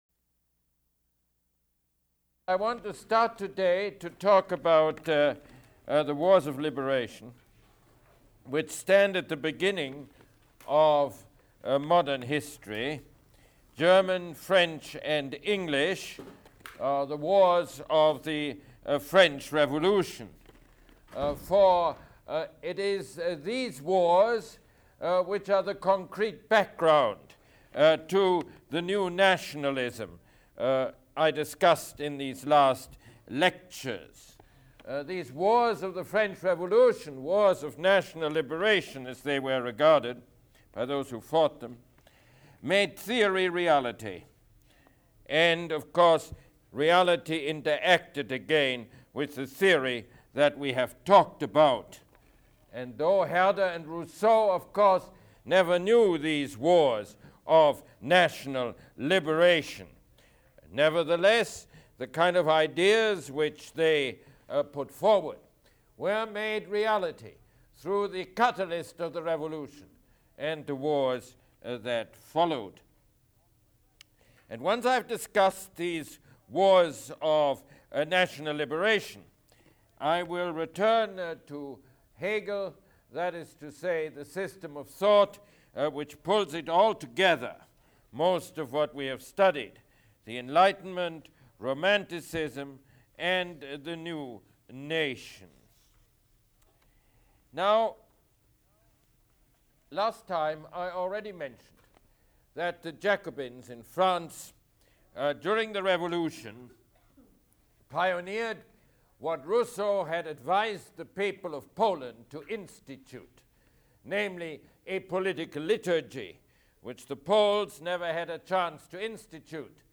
Mosse Lecture #19